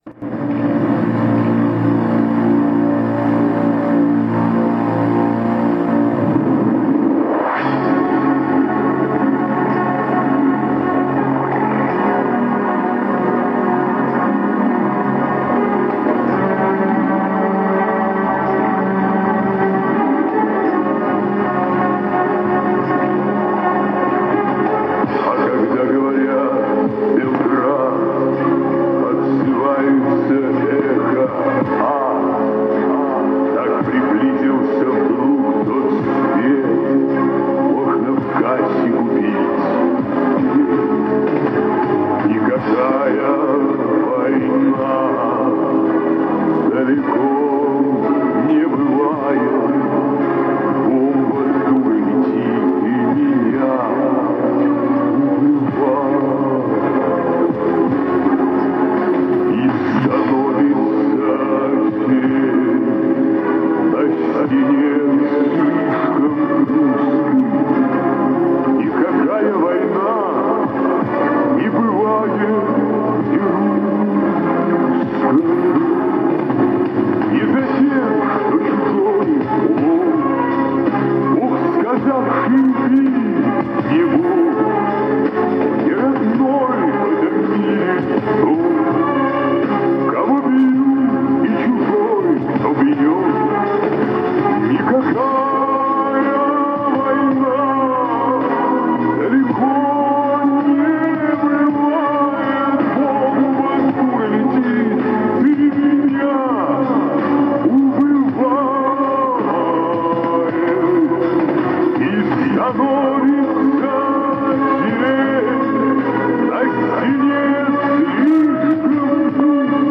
Негде је имам на касети, скинуту са радија.
Неким чудом сам пронашла касету, чудо да ради и касетофон.